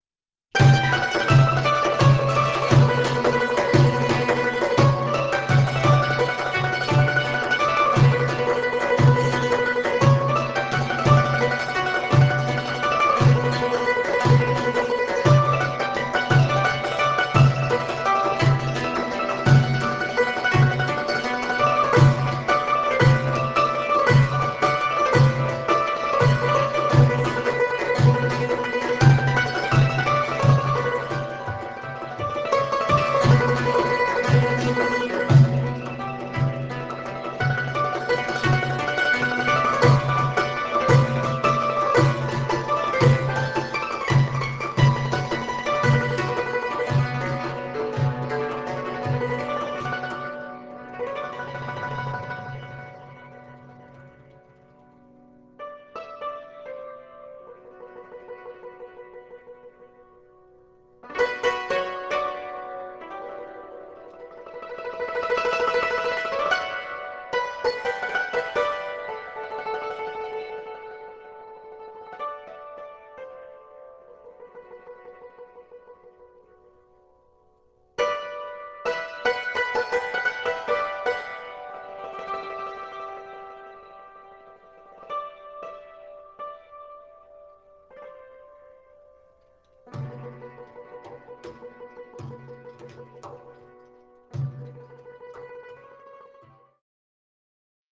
deff, zarb, tar, dumbek